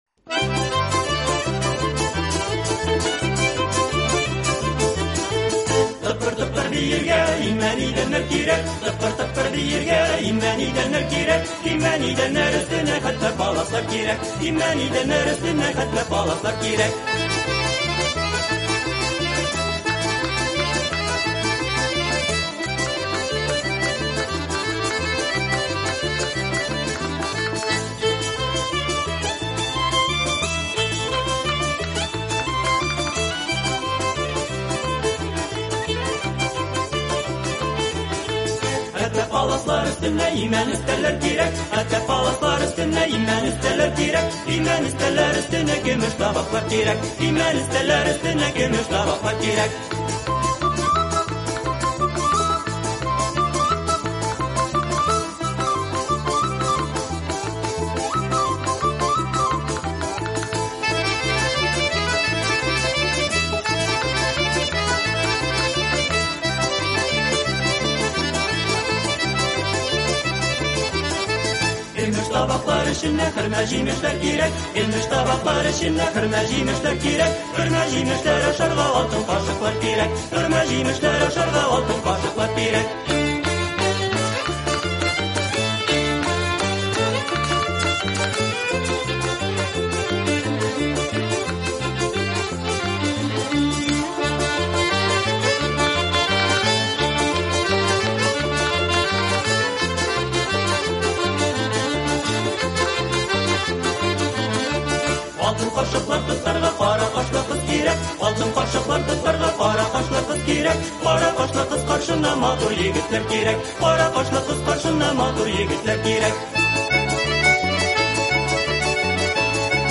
Сегодня у нас песня-частушка – такмаклар. Очень зажигательная и весёлая!